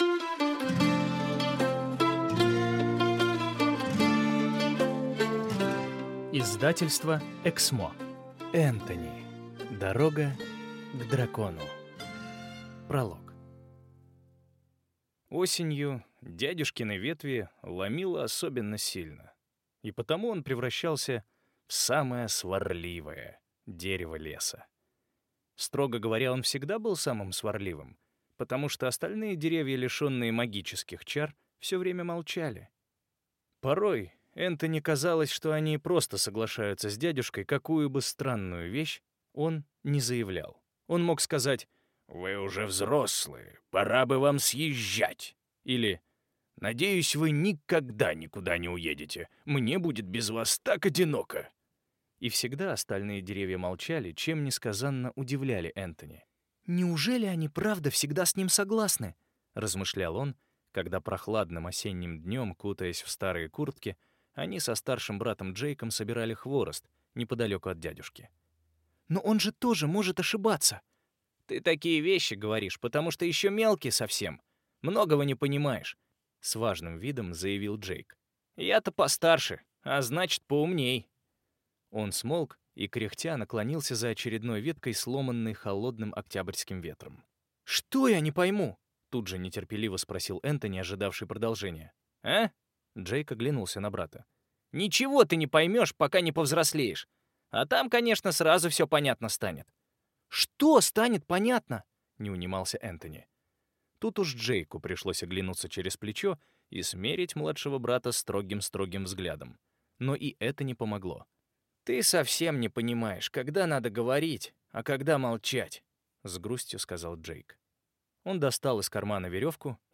Аудиокнига Энтони: Дорога к дракону | Библиотека аудиокниг